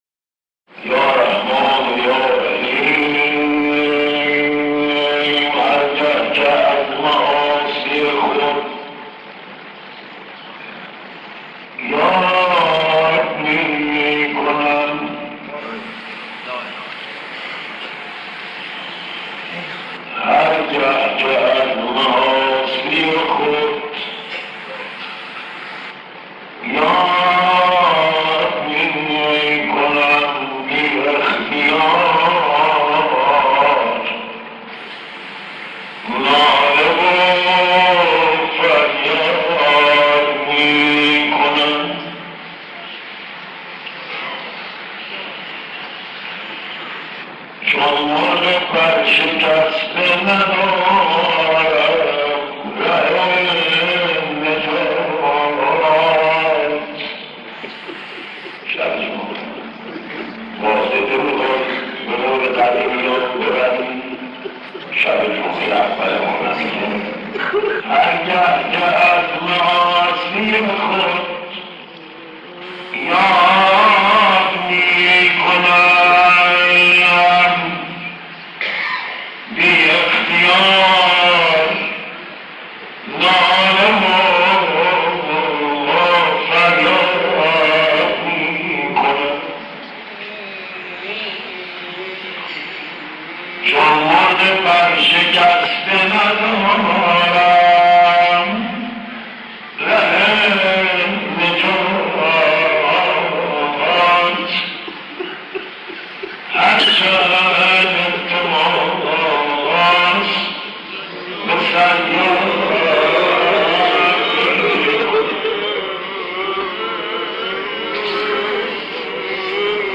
صوت | نغمه‌های عاشورایی از مداحان قدیم تهران
قصیده‌خوانی و غزل‌خوانی در مناجات توسل به اهل بیت (ع) و اشاره‌ای به حضرت علی اکبر (ع)